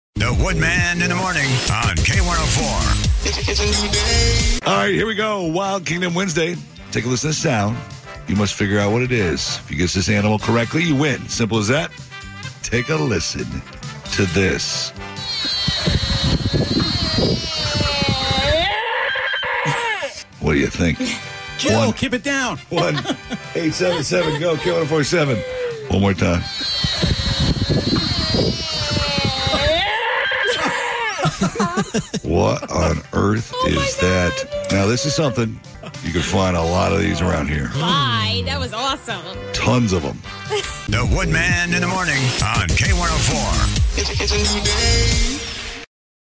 deerscreamssss